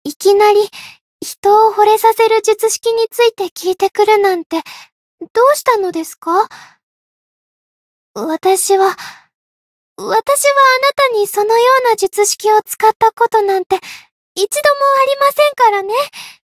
灵魂潮汐-伊汐尔-情人节（摸头语音）.ogg